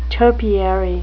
topiary (TOE-pee-er-ee) adjective
Pronunciation: